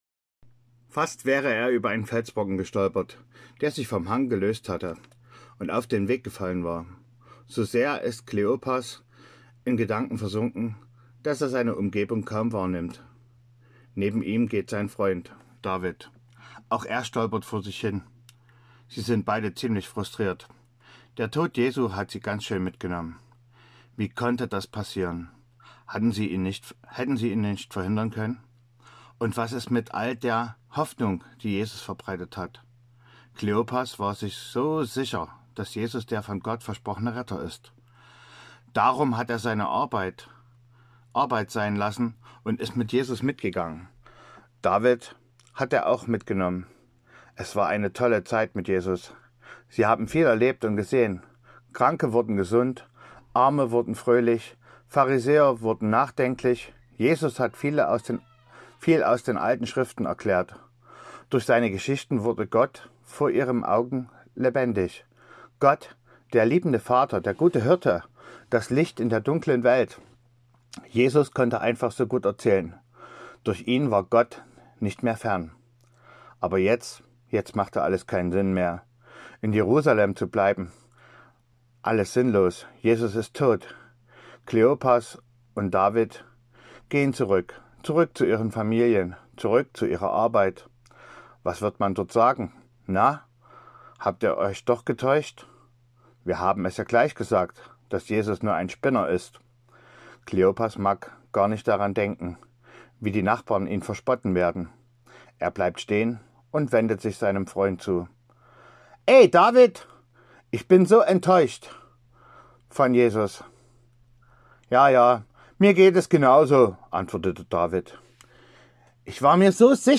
Hier die Hörspielgeschichten